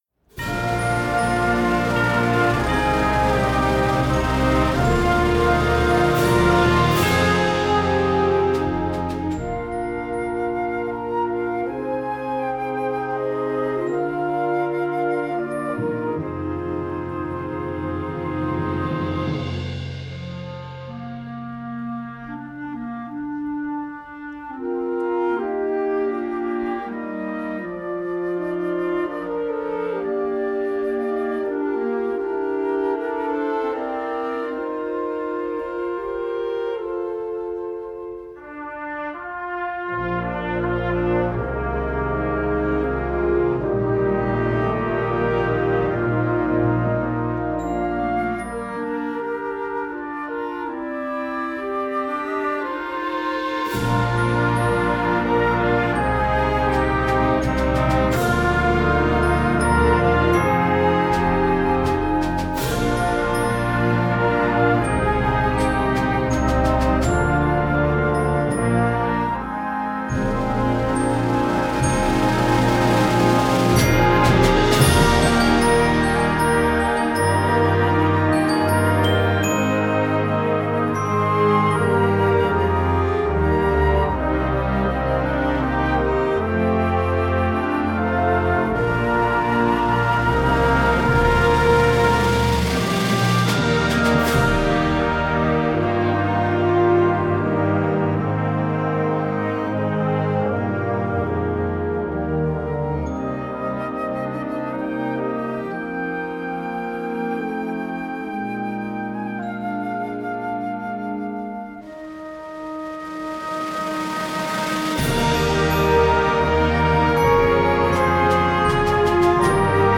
Besetzung: Blasorchester
ein pentatonisches Volkslied
die perfekte lyrische Ballade für eine Band der Mittelstufe.